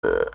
burp2.wav